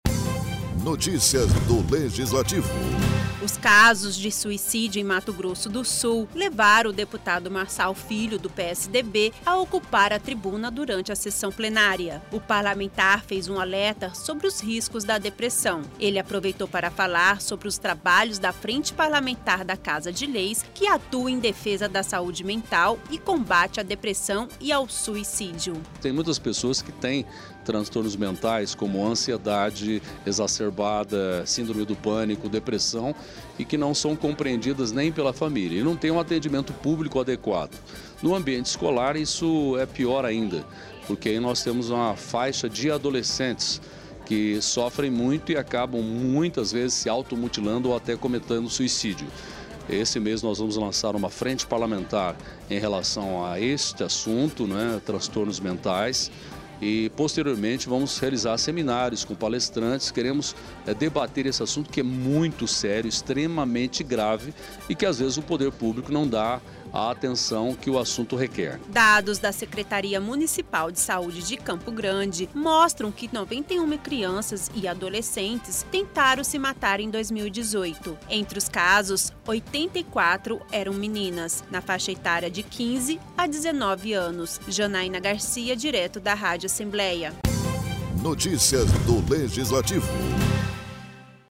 O deputado estadual Marçal Filho, do PSDB utilizou a tribuna da Casa de Leis e falou sobre a depressão e os trabalhos da Frente Parlamentar em Defesa da Saúde Mental e Combate à Depressão e ao Suicídio.